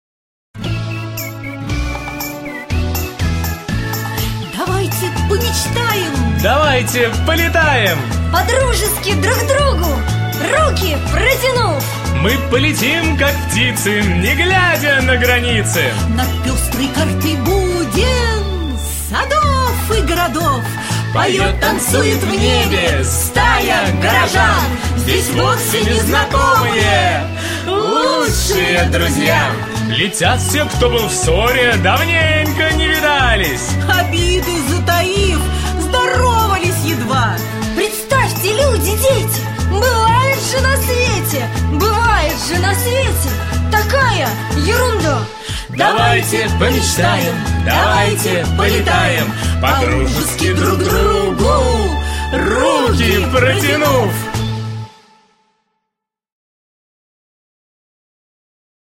Музыкальная вариация на тему колыбельной